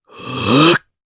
Riheet-Vox_Casting5_jp.wav